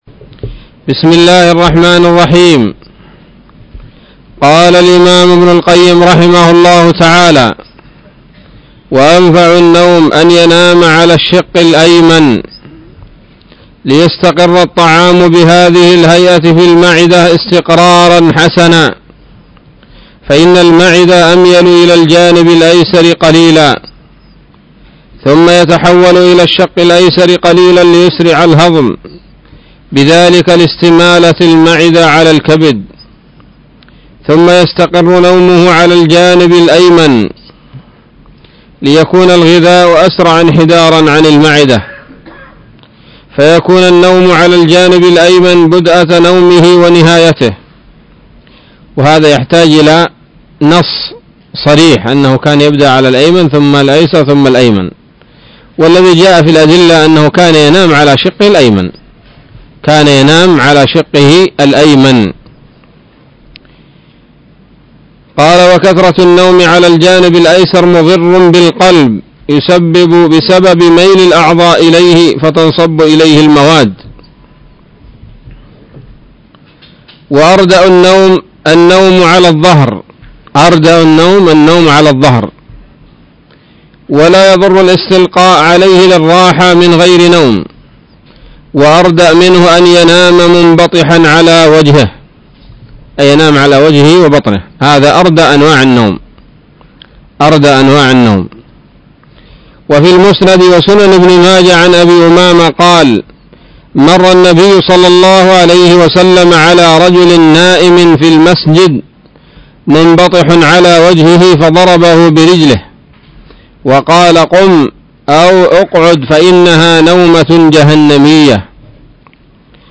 الدرس السادس والستون من كتاب الطب النبوي لابن القيم